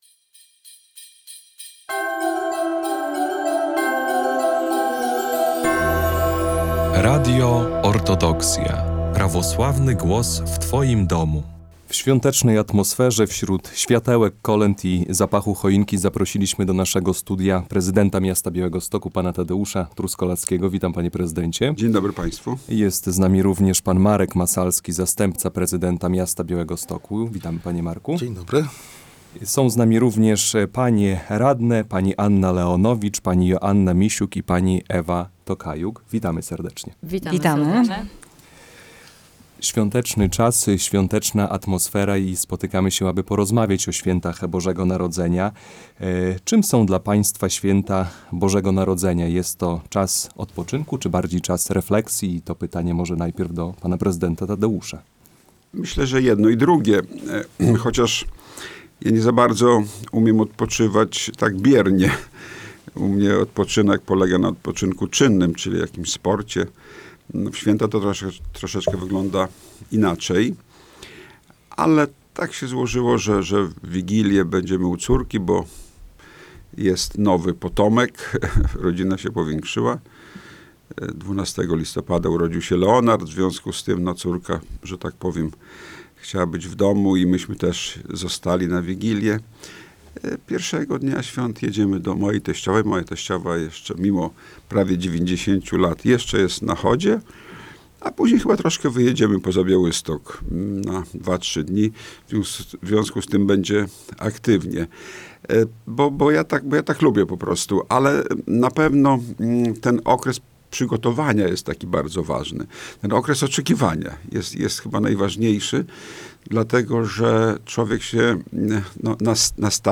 Świąteczna audycja z prezydentem Tadeuszem Truskolaskim, zastępcą Markiem Masalskim i radnymi miasta Białystok